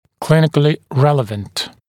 [‘klɪnɪklɪ ‘reləvənt][‘клиникли ‘рэлэвэнт]имеющий значение в клинической практике